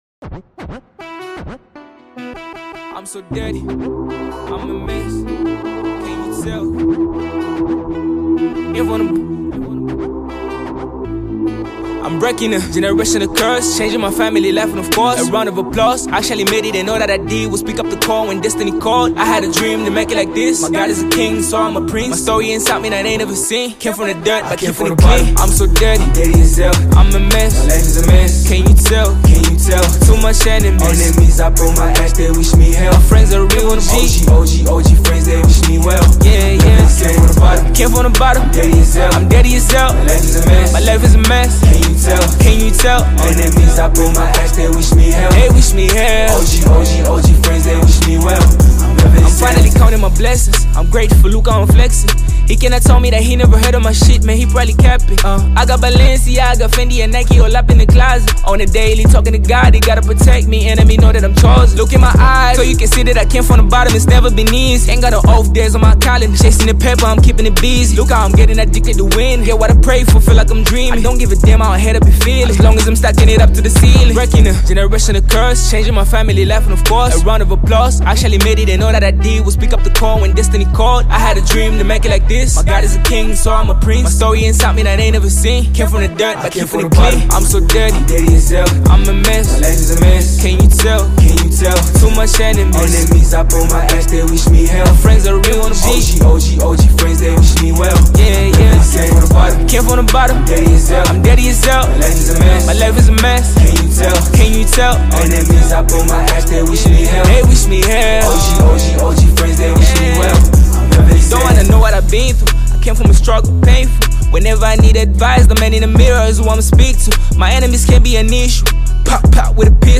heartfelt track